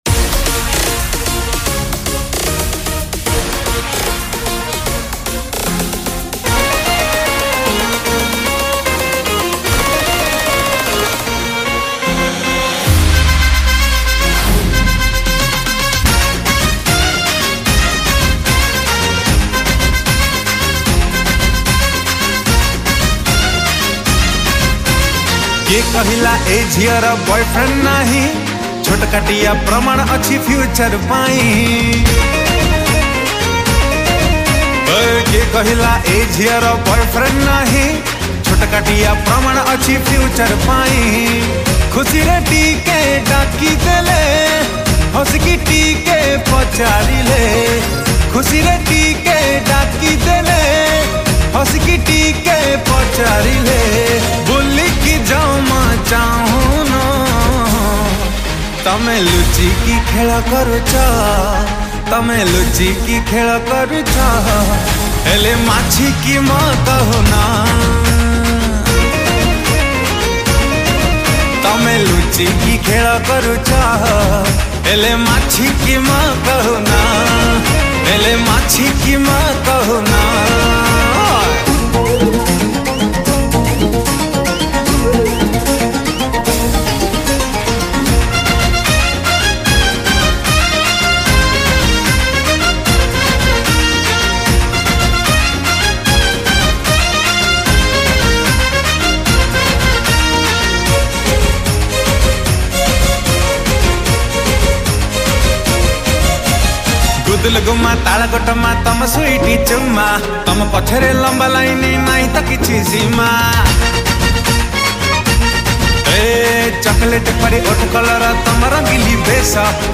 Studio Version